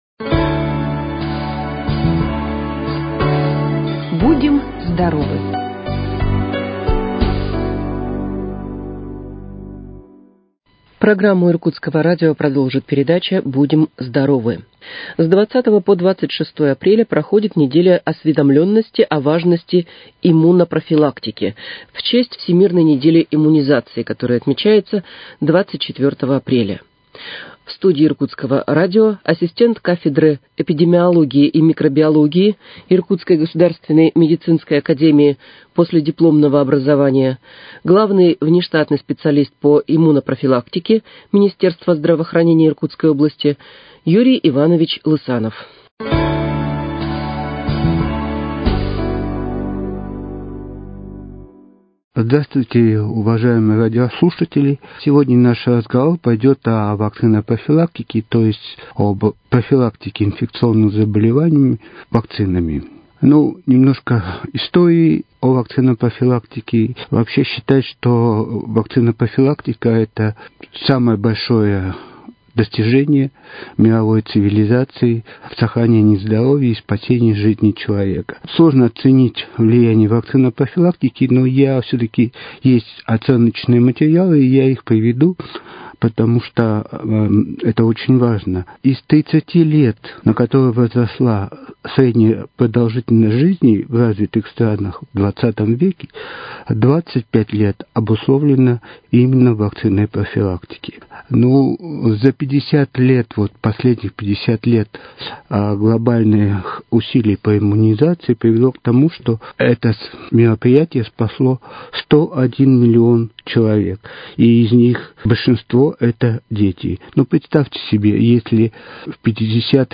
С 20 по 26 апреля проходит Неделя осведомленности о важности иммунопрофилактики (в честь Всемирной недели иммунизации 24 апреля). В студии Иркутского радио